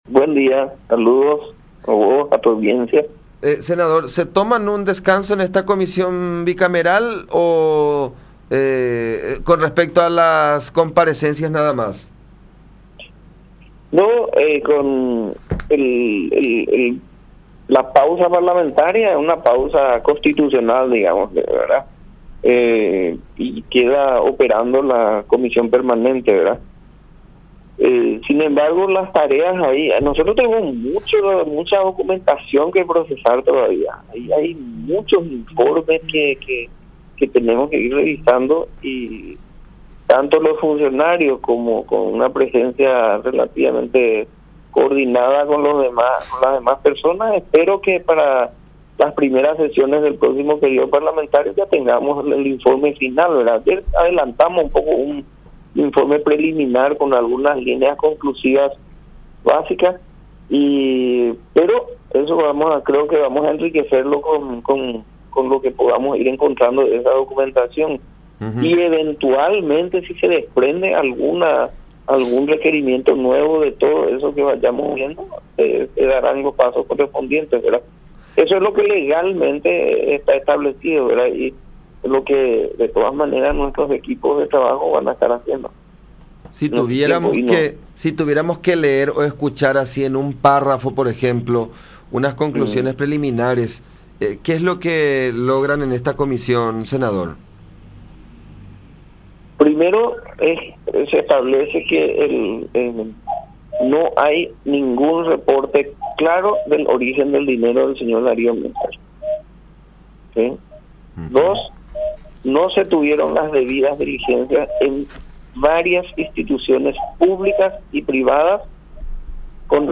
“Tanto intentaron desacreditar la legalidad de la Comisión Bicameral, pero el Juez Corbeta ratificó las atribuciones y facultades de esta instancia, eso es importante”, resaltó en comunicación con La Unión.
04-SENADOR-JORGE-QUEREY-1.mp3